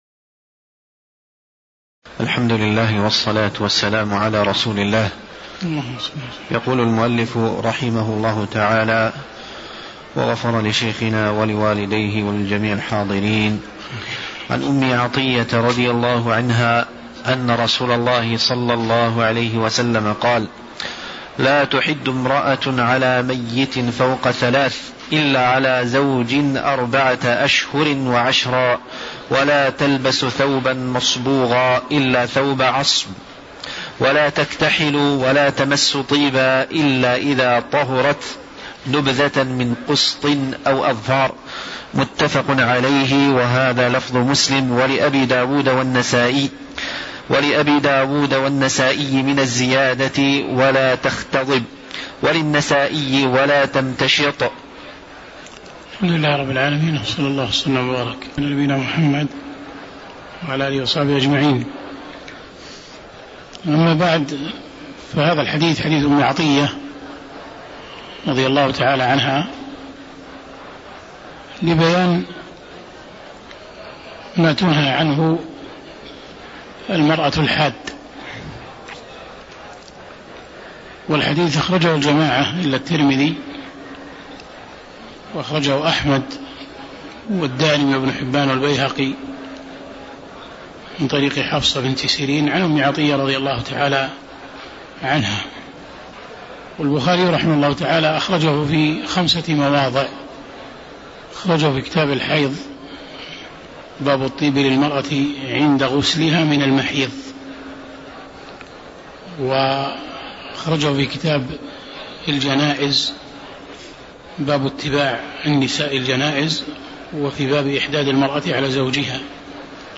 تاريخ النشر ٢٠ محرم ١٤٣٩ هـ المكان: المسجد النبوي الشيخ